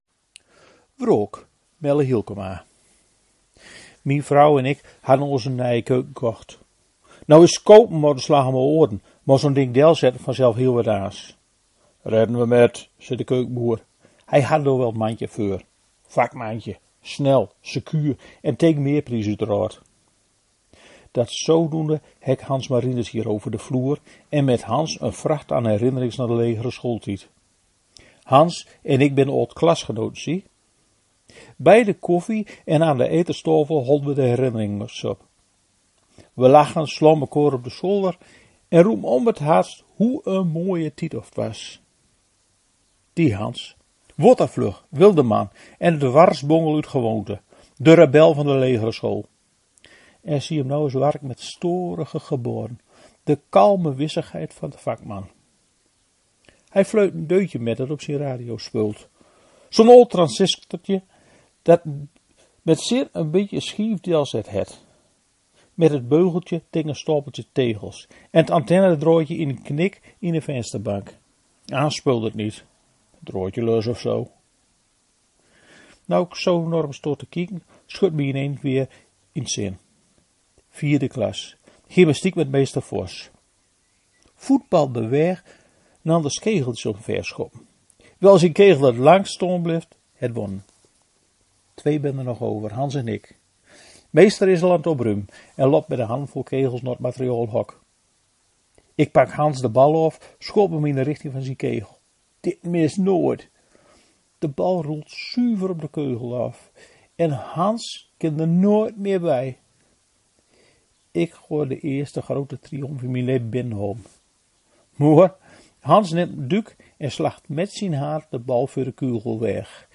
Ook binnen het Westerkwartiers hoor je verschillen die te maken hebben met de ligging, b.v. dicht bij de grens met Friesland of in het noorden.
Beiden hebben hun oorspronkelijke klank behouden zoals goed te horen is in hun verhaal en gedicht.